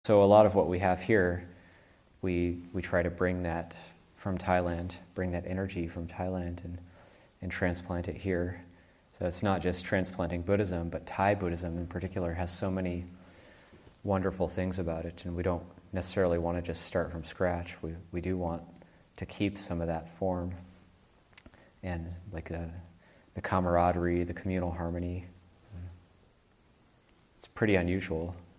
Abhayagiri 25th Anniversary Retreat [2021], Session 11, Excerpt 5